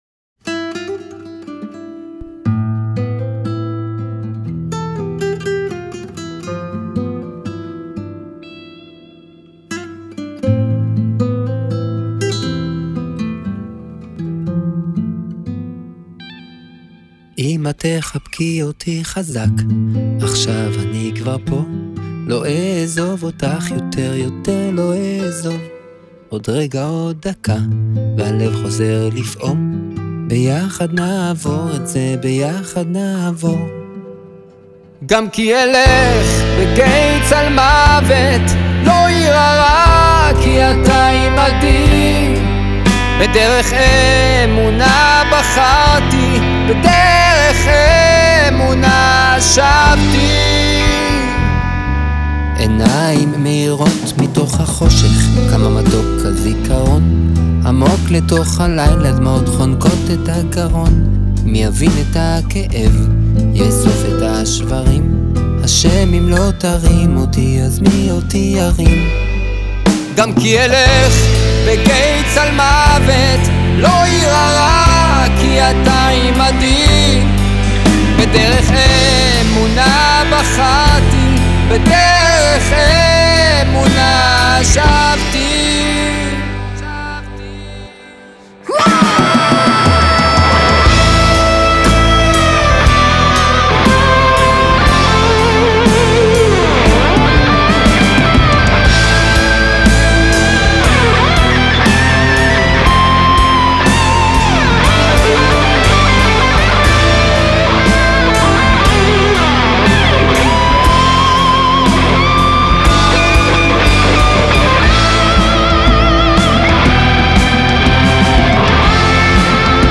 הוא מסע מוזיקלי מטלטל, שנוגע בעומק הלב